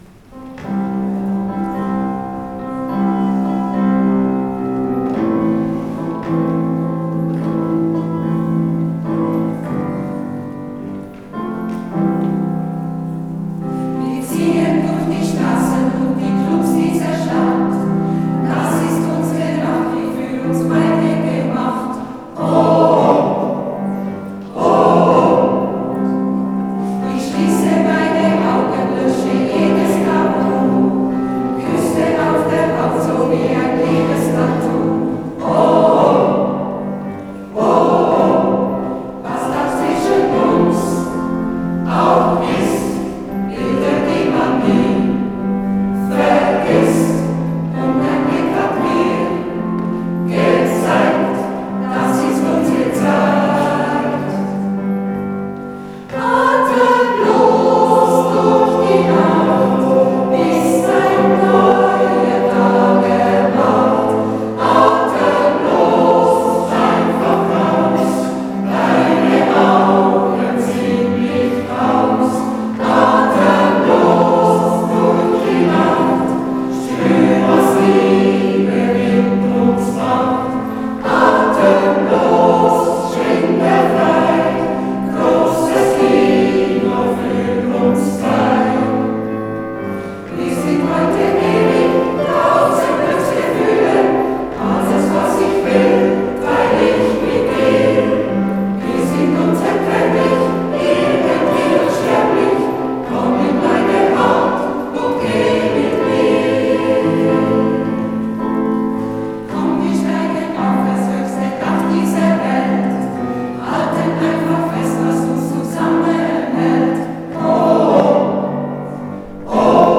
Muttertagskonzert 2025